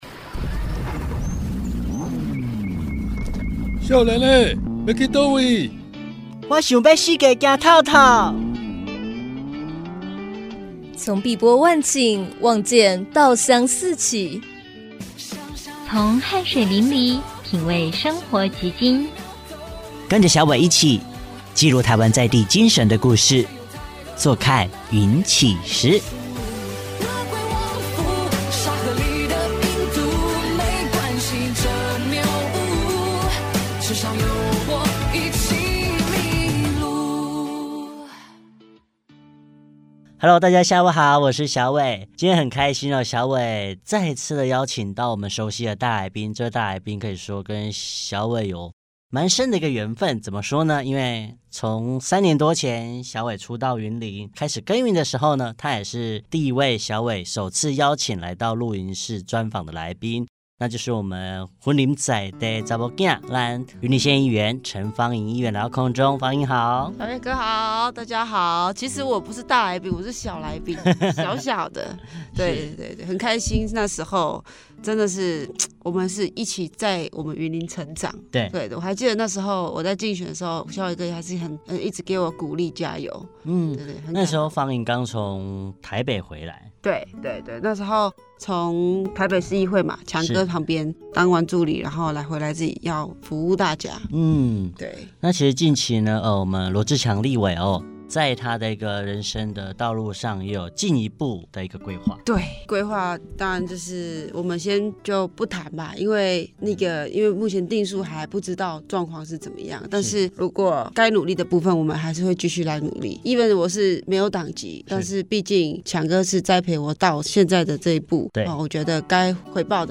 這一集，我們沒有訪問，只有對話。 像老朋友一樣，從初見聊到現在，從痛苦聊到希望，從無常聊到堅持。